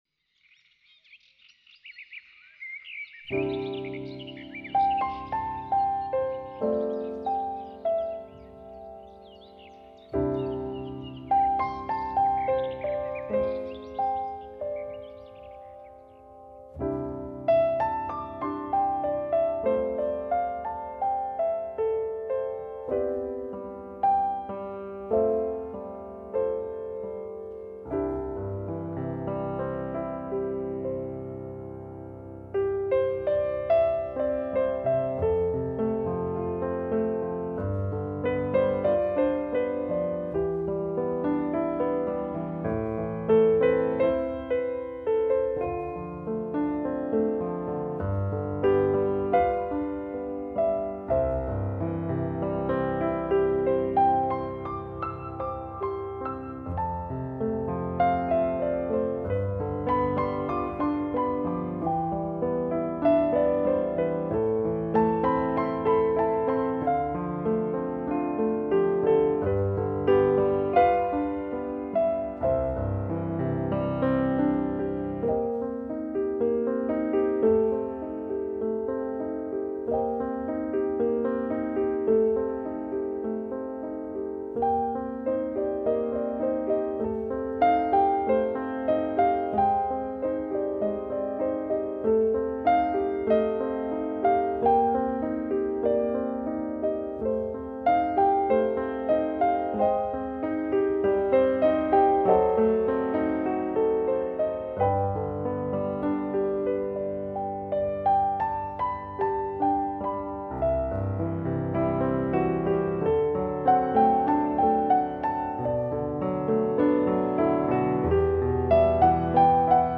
休闲音乐
钢琴独奏辑
听新世纪钢琴 诉说思念与离别
一个钢琴家令人流连忘返的发烧录音